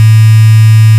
bitCrusher.wav